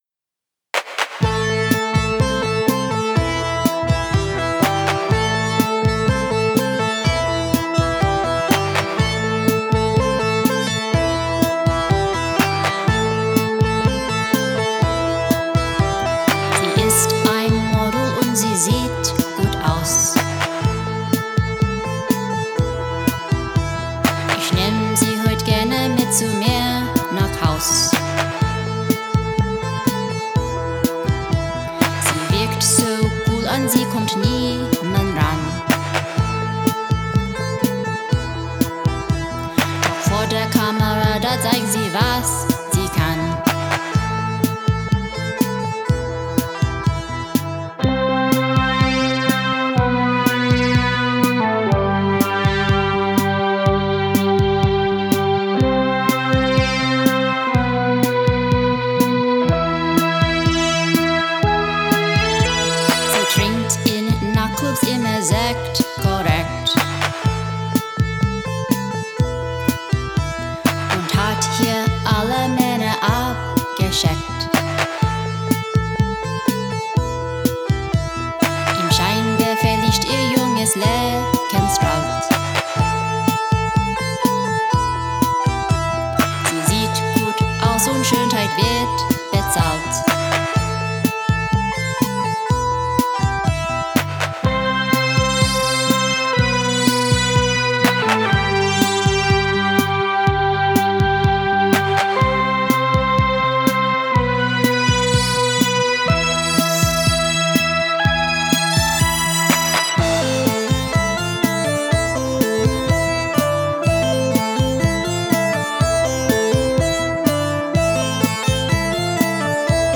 Why not vintage keyboard emulating software?
Secondly, have a lady sing it.